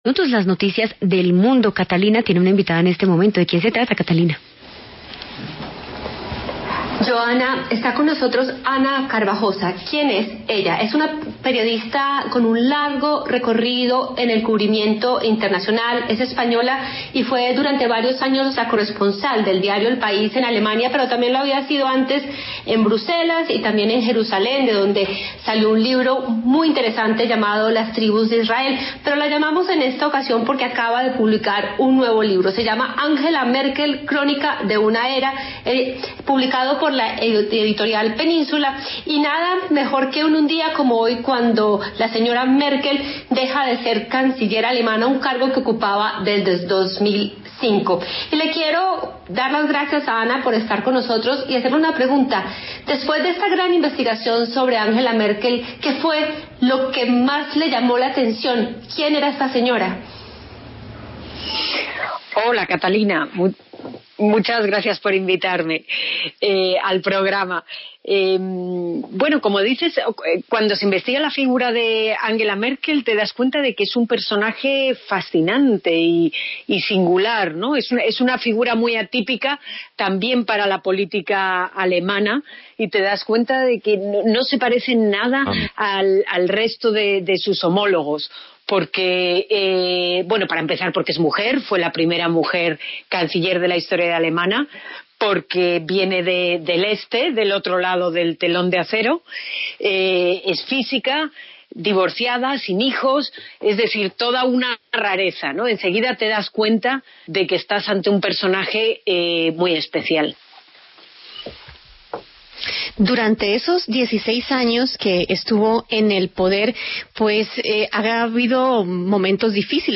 conversó con La W sobre los cuatro mandatos consecutivos de la saliente canciller.